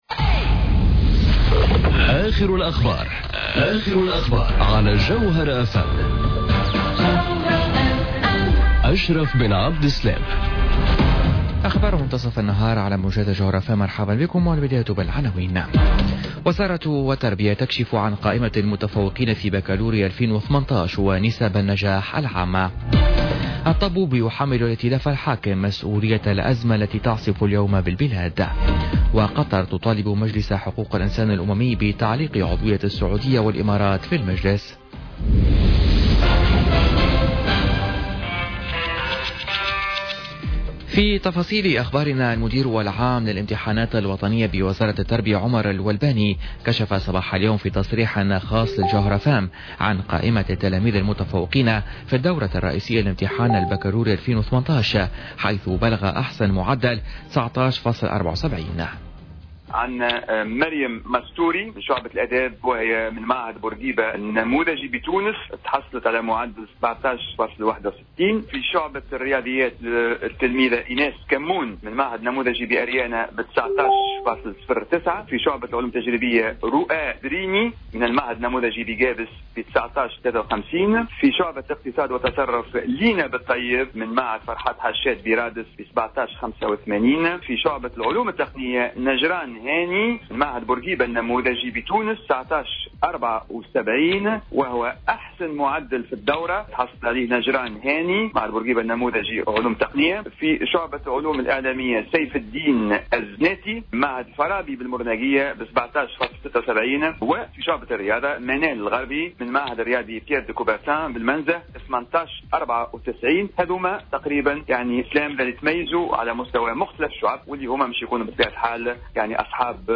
نشرة أخبار منتصف النهار ليوم السبت 23 جوان 2018